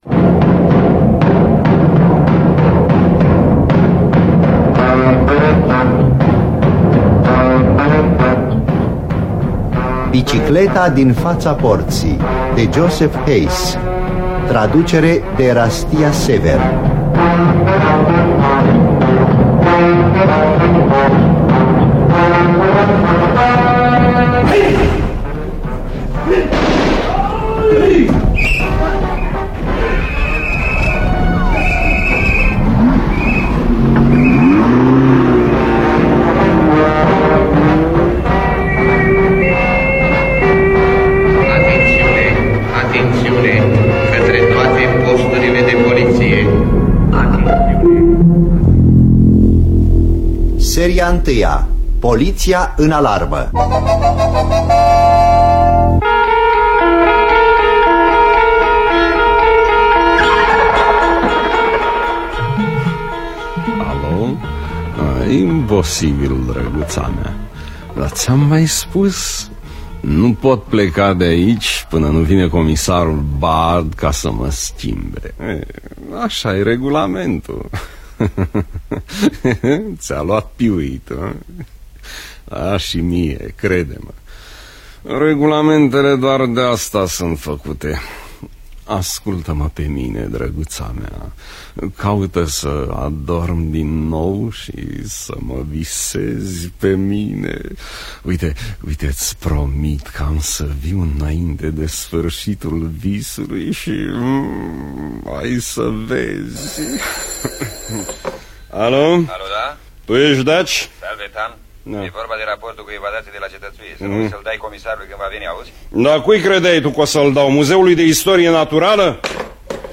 Joseph Hayes & Marc-Gilbert Sauvajon – Bicicleta Din Fata Portii (1968) – Episodul 1 – Teatru Radiofonic Online